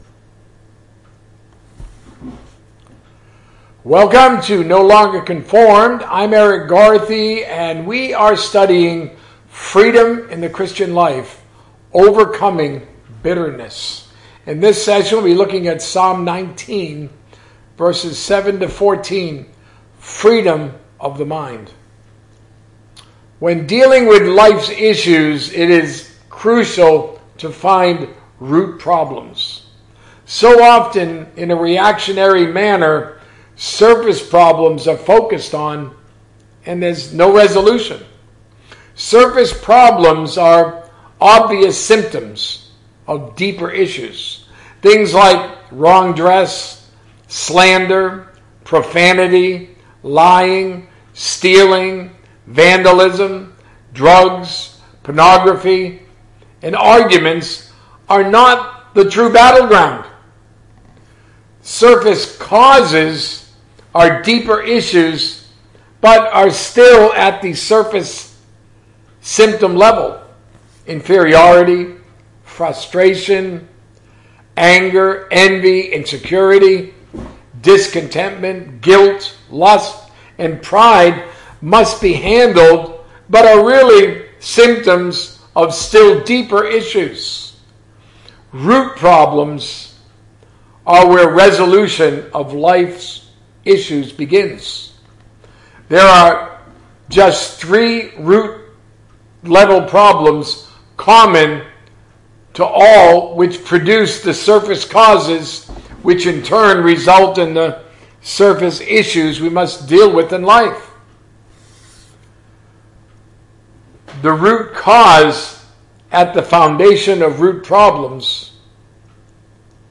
A message from the series "God's Will."